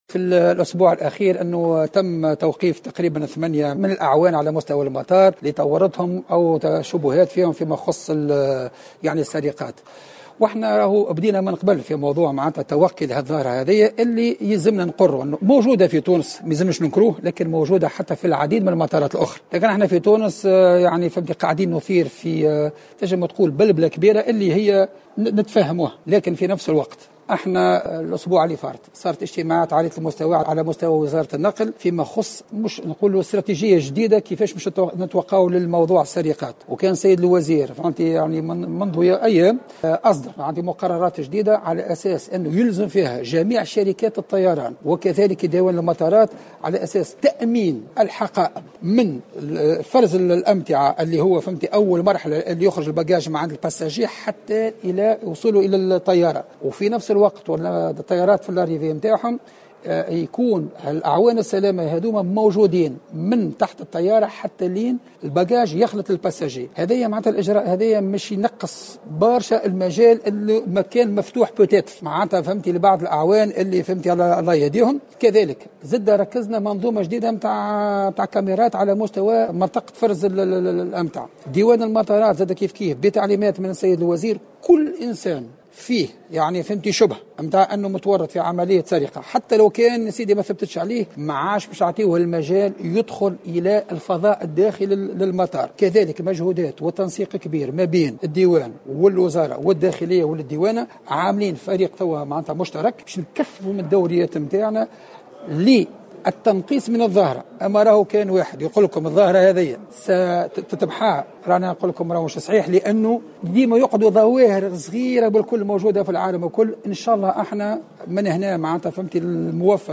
وأعلن الشلي في تصريح لمراسلة الجوهرة أف أم، على هامش الملتقى الإفريقي السادس للجامعة الدولية لفنيي الكترونيك سلامة الحركة الجوية، الذي انطلقت أشغاله اليوم الخميس في الحمامات، أعلن أن وزير النقل قد أصدر منذ أيام قرارات جديدة تلزم جميع شركات الطيران وإدارات المطارات بتأمين حقائب المسافرين بداية من مرحلة فرز الأمتعة وصولا إلى مرحلة شحنها على متن الطائرات.